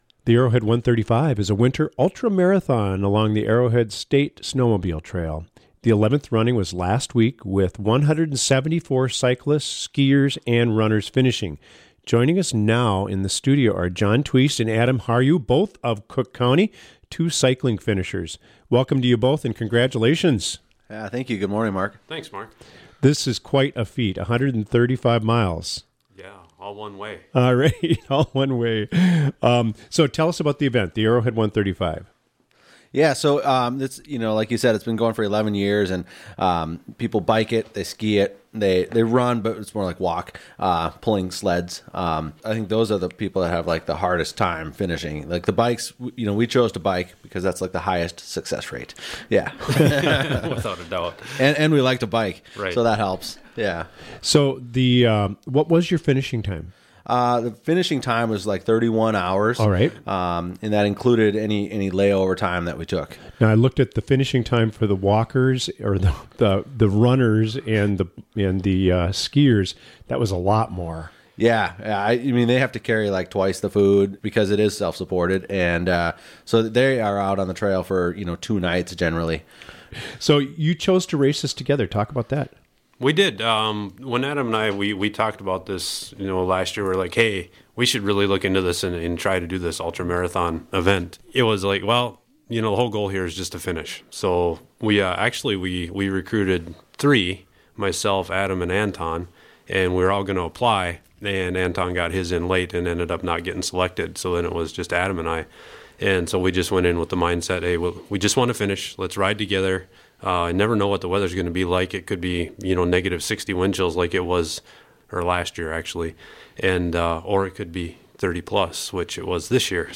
Two Cook County cyclists recently completed The Arrowhead 135, a winter ultra-marathon in northern Minnesota.